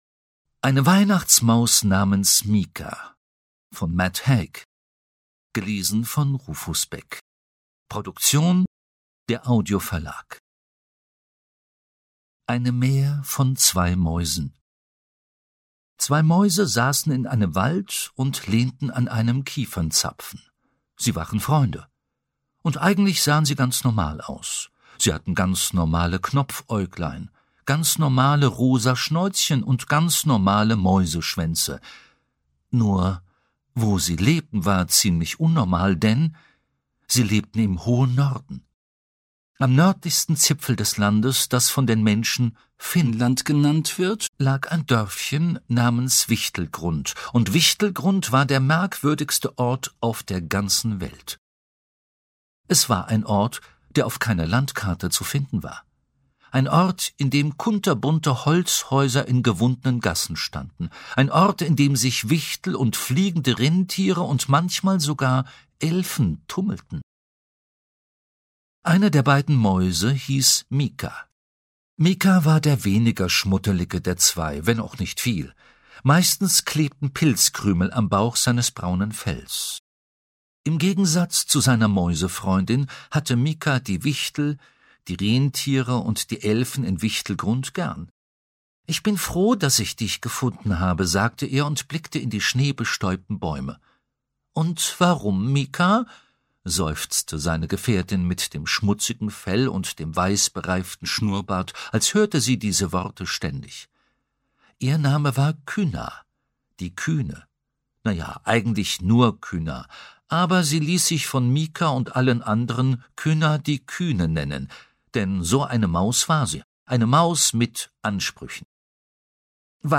Ungekürzte Lesung mit Rufus Beck (2 CDs)
Rufus Beck (Sprecher)